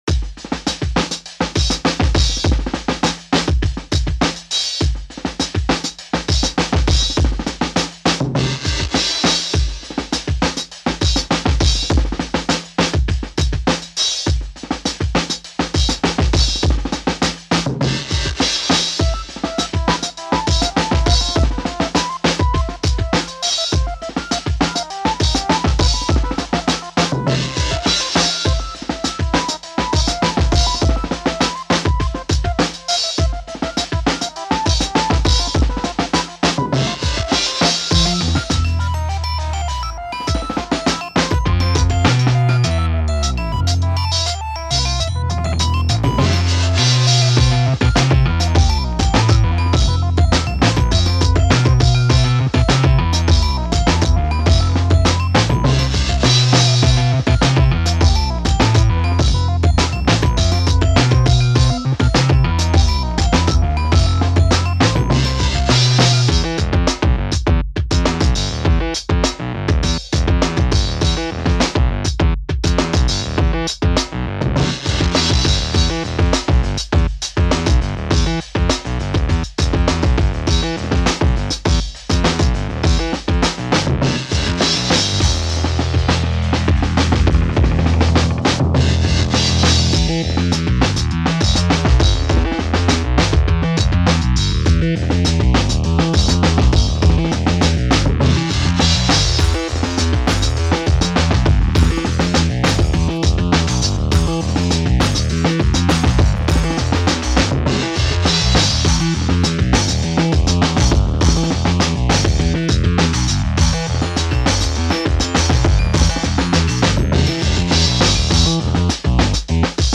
These "songs" are just the results of me playing with Renoise. I usually drop some sounds to the timeline and listen to them looped.